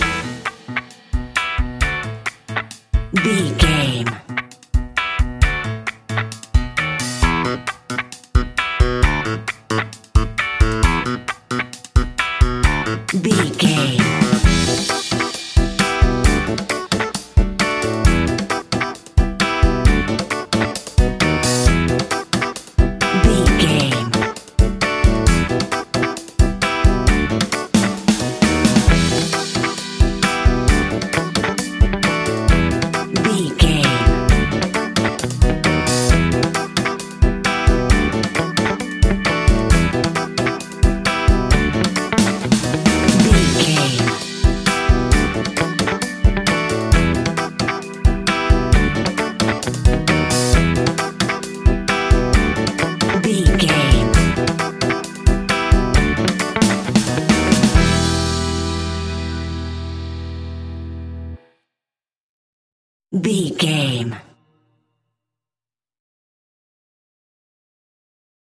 A groovy piece of upbeat Ska Reggae!
Aeolian/Minor
Fast
laid back
off beat
drums
skank guitar
hammond organ
percussion
horns